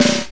Index of /90_sSampleCDs/Northstar - Drumscapes Roland/DRM_R&B Groove/SNR_R&B Snares x